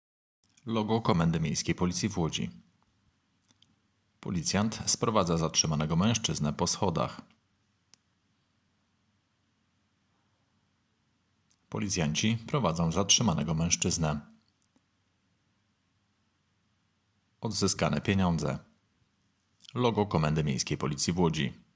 Nagranie audio deskrypcja_filmu.m4a